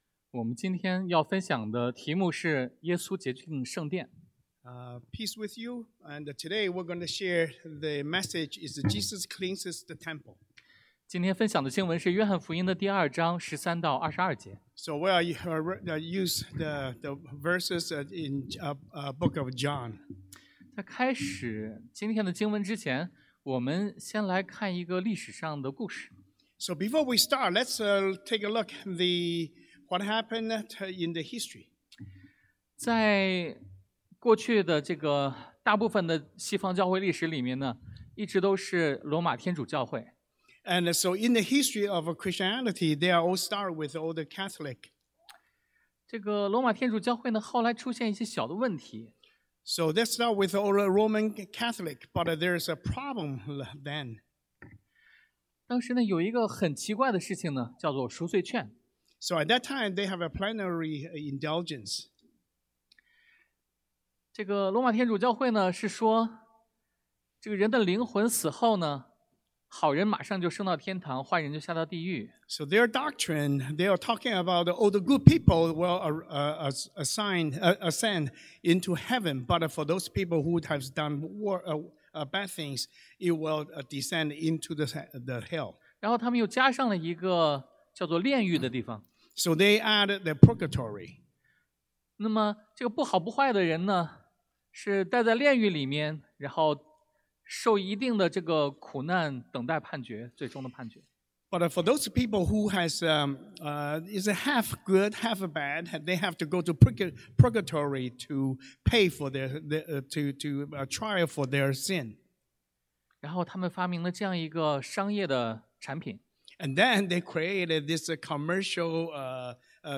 Service Type: Sunday AM Sacred Anger 神圣的愤怒 Supreme Authority 至高的权柄 The True Temple 真正的圣殿